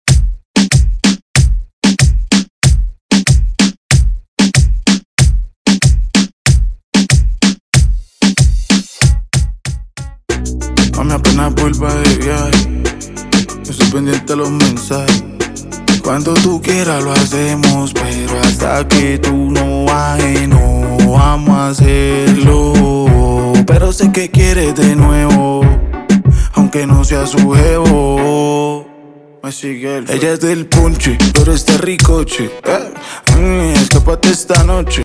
His style is unique and electrifying.
DJ